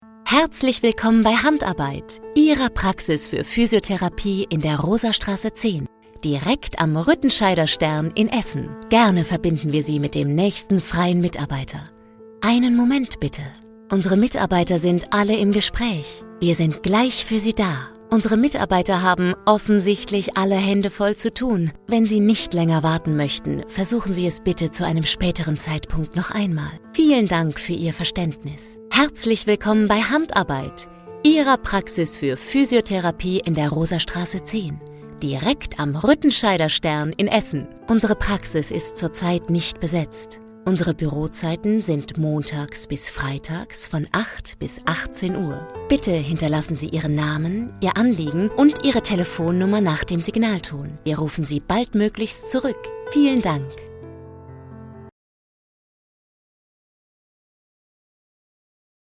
facettenreich,dynamisch, kräftig, schrill, kindlich, lieblich, verführerisch, warm, Schauspielerin, Sängerin
Sprechprobe: eLearning (Muttersprache):